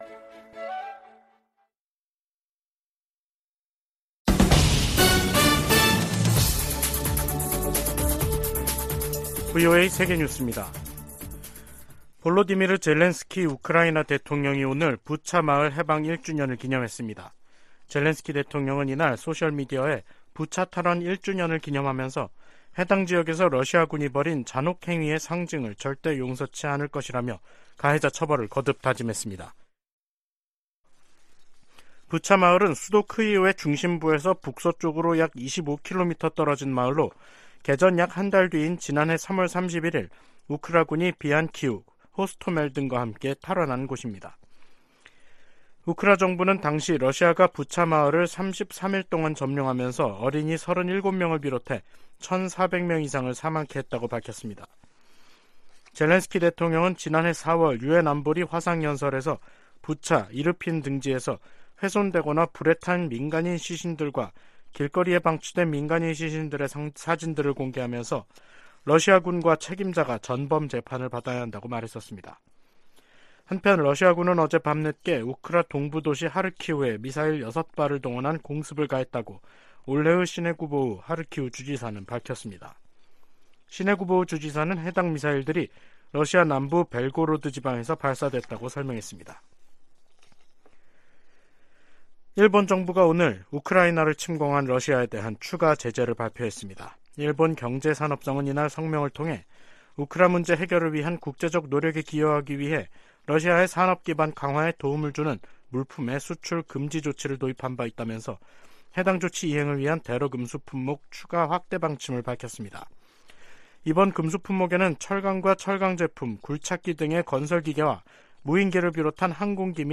VOA 한국어 간판 뉴스 프로그램 '뉴스 투데이', 2023년 3월 31일 2부 방송입니다. 미 재무부가 북한과 러시아의 무기 거래에 관여한 슬로바키아인을 제재 명단에 올렸습니다. 백악관은 러시아가 우크라이나 전쟁에서 사용할 무기를 획득하기 위해 북한과 다시 접촉하고 있다는 새로운 정보가 있다고 밝혔습니다. 미 국방부는 핵무기 한국 재배치 주장에 한반도 비핵화 정책을 계속 유지할 것이라고 밝혔습니다.